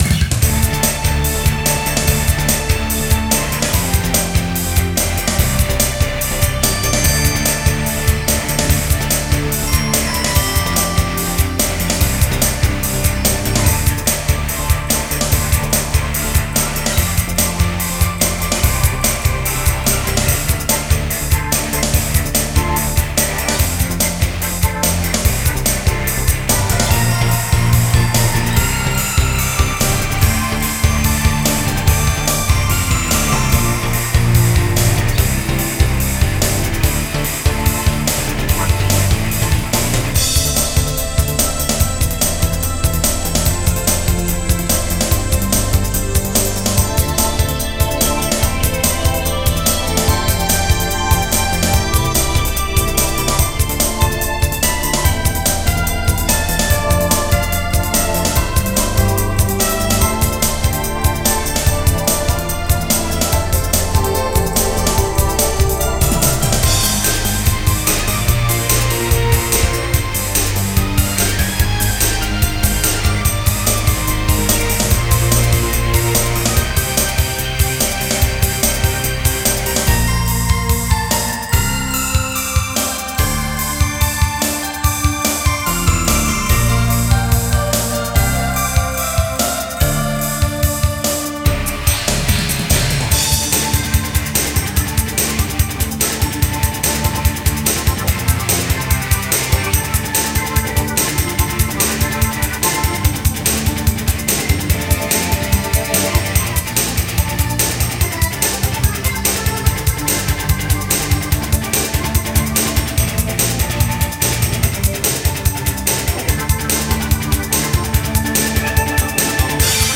Lots of blues riffs and hammond organ with rotary cabiinet.